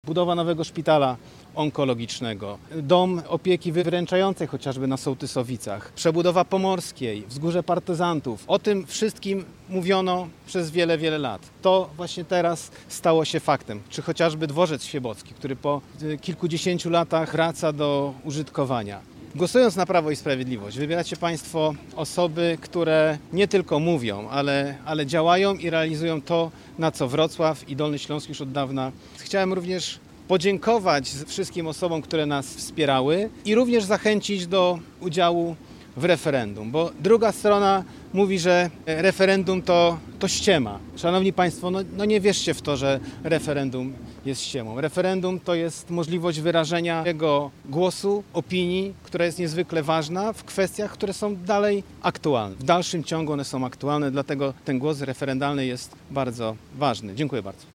Na Placu Gołębim we Wrocławiu zgromadzili się senatorowie i posłowie, a także osoby ubiegające się o mandat do parlamentu.